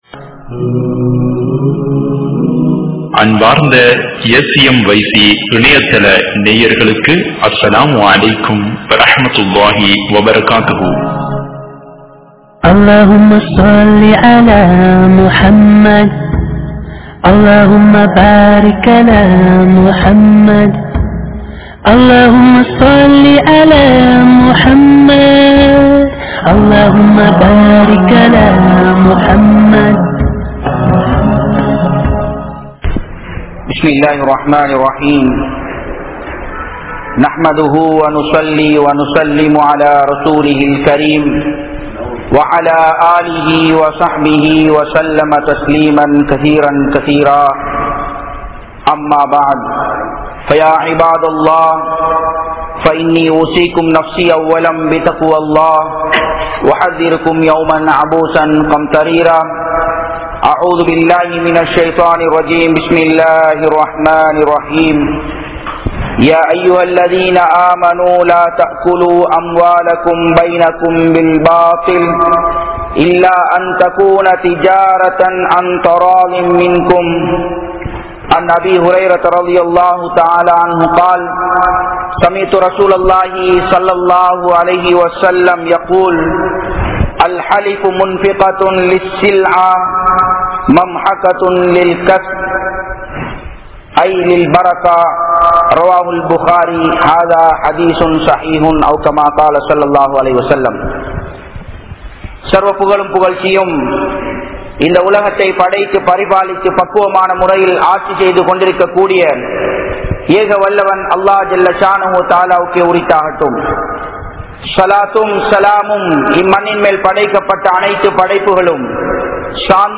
Viyafaaraththil Nastam Ean? (வியாபாரத்தில் நஷ்டம் ஏன்?) | Audio Bayans | All Ceylon Muslim Youth Community | Addalaichenai